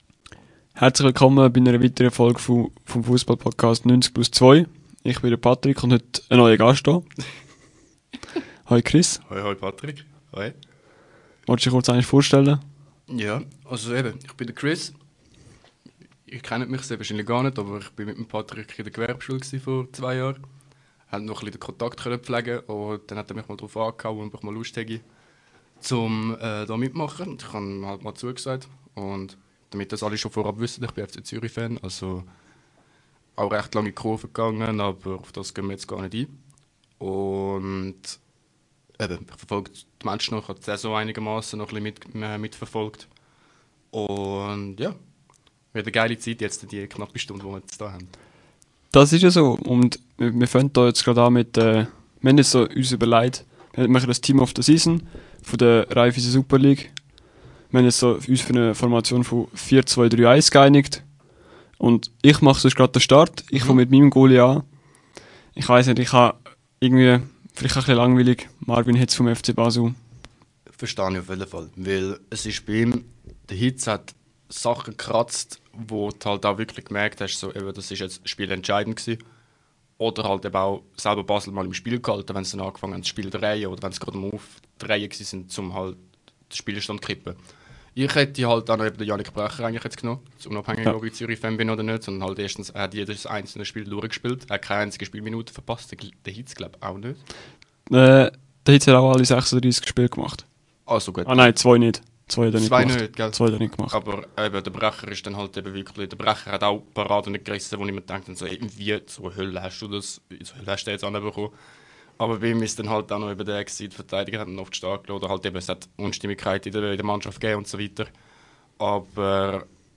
In dieser Folge reden ich mit einem neuen Gast über die Saison von der Raiffeisen Super League. Welche Spieler haben uns überrascht, welche haben uns enttäuscht. Wir haben unser Team of The Season präsentiert und weitere Awards an Spieler verliehen.